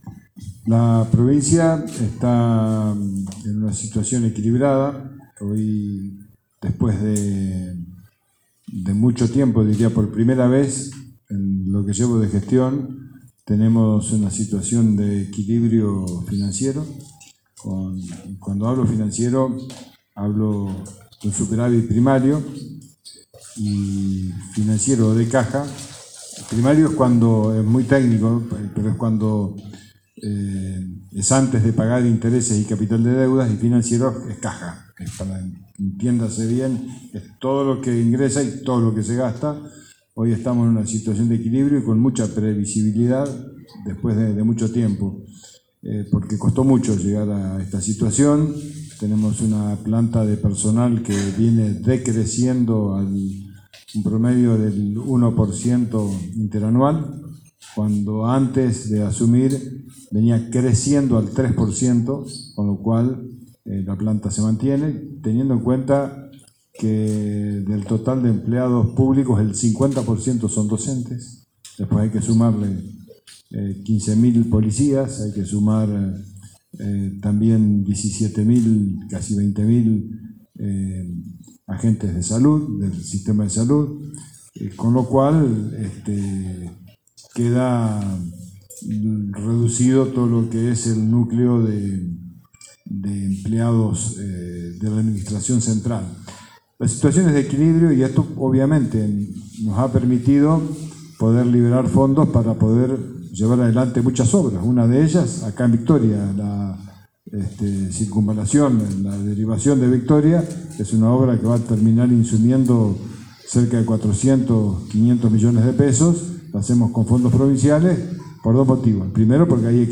Bordet visitó la ciudad de Victoria y brindó una conferencia de prensa en el Hotel Sol Victoria.